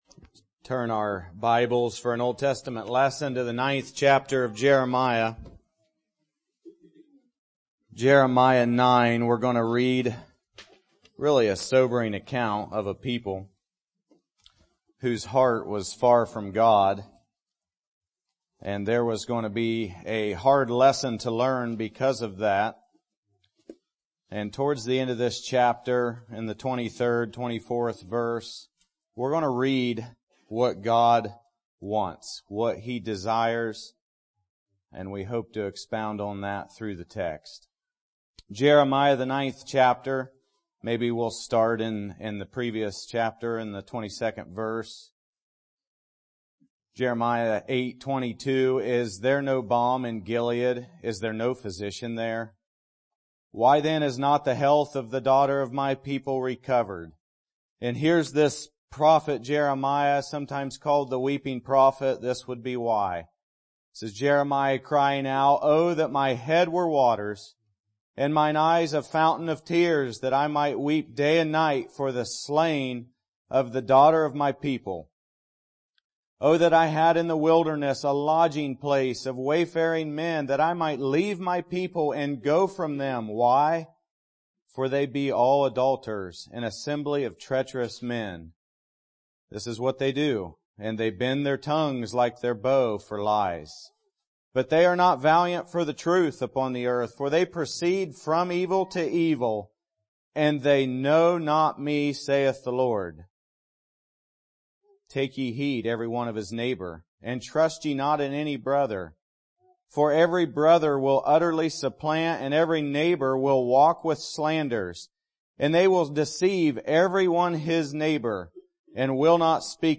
OGBBC Sermons